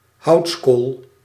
Ääntäminen
Ääntäminen France (Île-de-France): IPA: /ʃaʁ.bɔ̃ də bwa/ Paris: IPA: [ʃaʁ.bɔ̃ də bwa] Haettu sana löytyi näillä lähdekielillä: ranska Käännös Ääninäyte 1. houtskool {m} Suku: m .